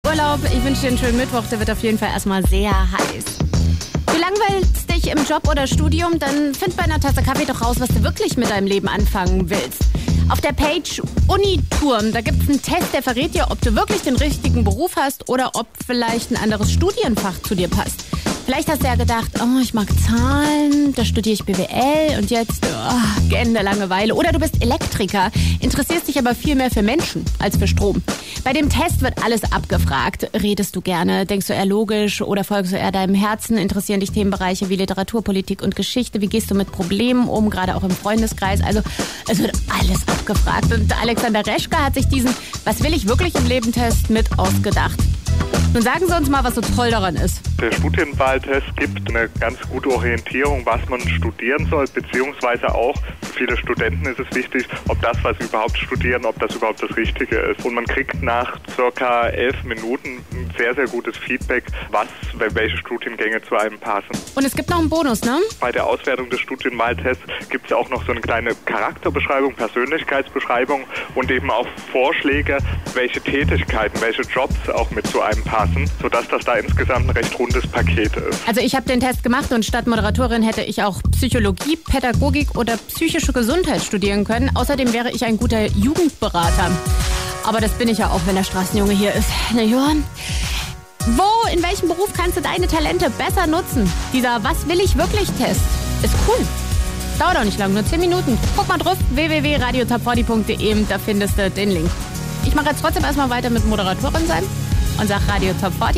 Radio-Interview zum Studienwahltest auf radio TOP 40